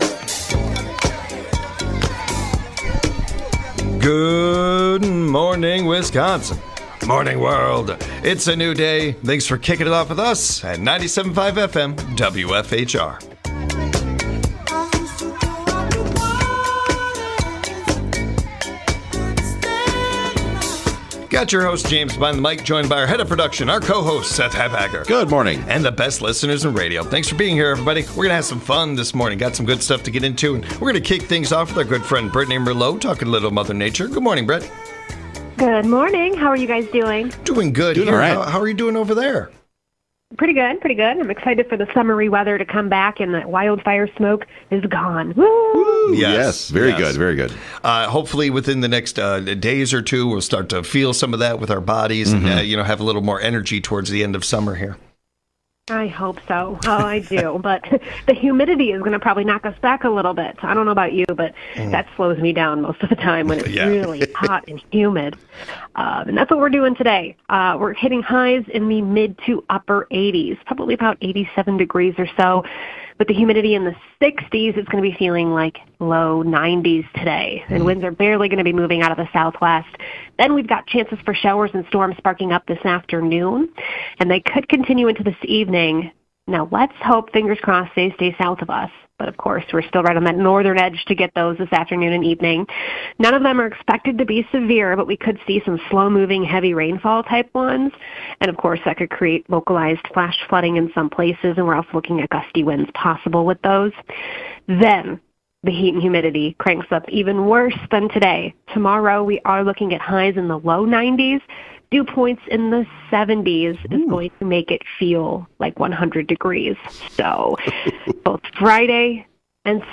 The guys have a discussion about drones and food.